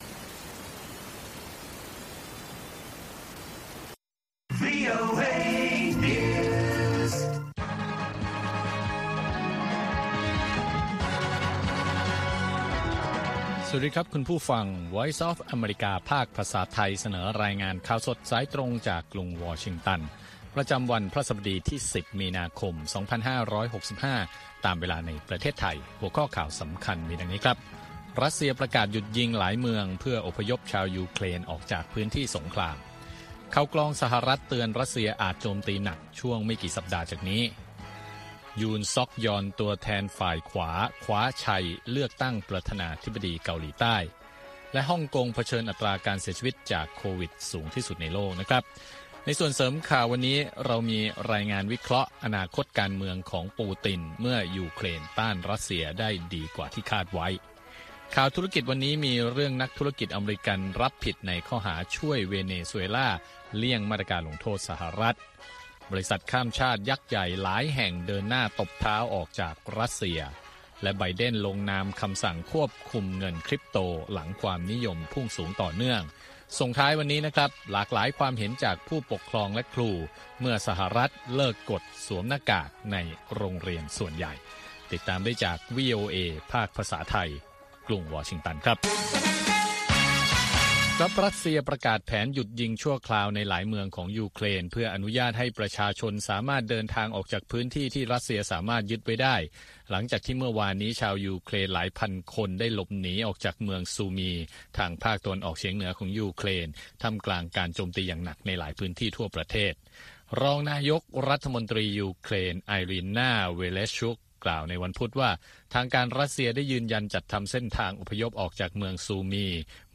ข่าวสดสายตรงจากวีโอเอ ภาคภาษาไทย 6:30 – 7:00 น. ประจำวันพฤหัสบดีที่ 10 มีนาคม 2565 ตามเวลาในประเทศไทย